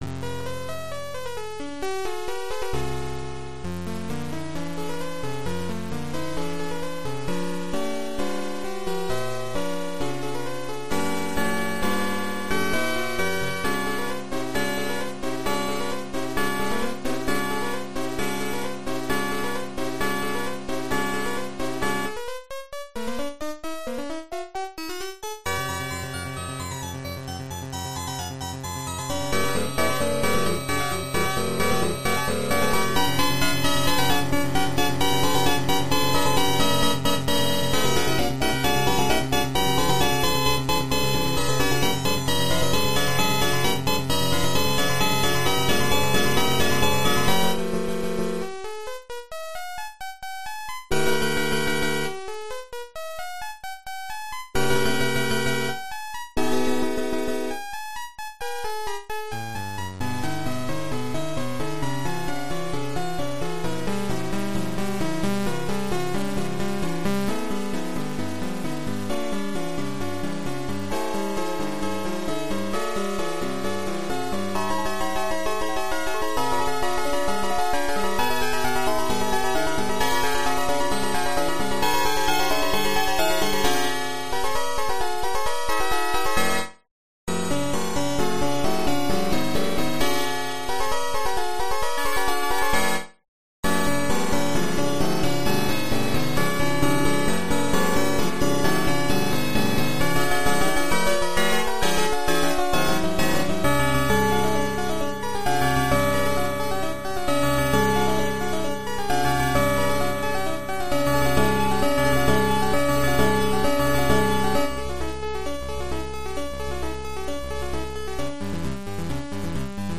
About ten minutes of what would be an admittedly-mechanical rendition of beautiful Romantic-era orchestral music, except most of the notes are wildly out of tune.
...how did someone make an out-of-tune /soundfont/ is this an art piece
(...maybe the soundfont is unfinished? maybe the version I downloaded is broken? I don't understand)